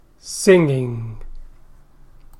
sound_loud_speaker singing     /sɪŋɪŋ/